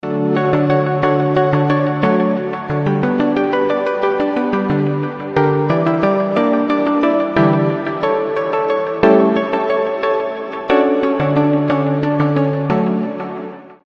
• Качество: 320, Stereo
без слов
пианино
приятные
Звук, сыгранный на пианино